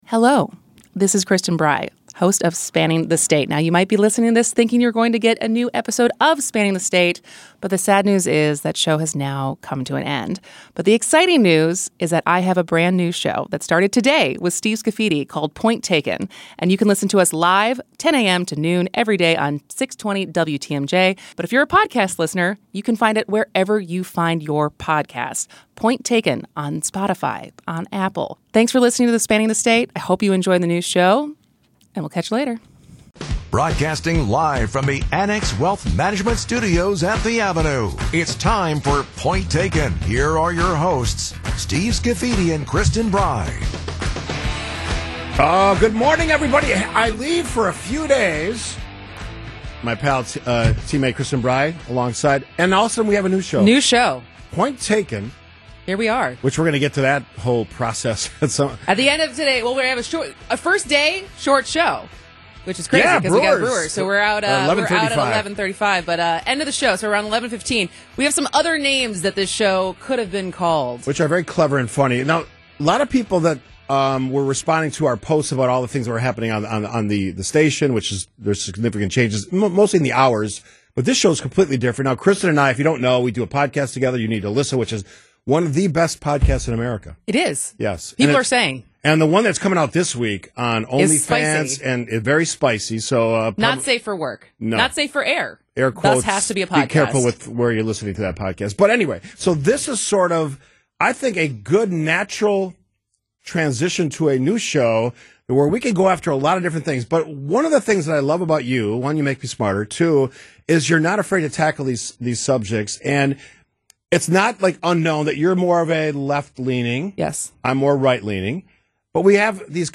Be a guest on this podcast Language: en Genres: News , News Commentary , Politics Contact email: Get it Feed URL: Get it iTunes ID: Get it Get all podcast data Listen Now...
They play audio from ABC Radio and discuss.